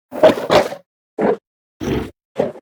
Sfx_creature_snowstalkerbaby_sniff_01.ogg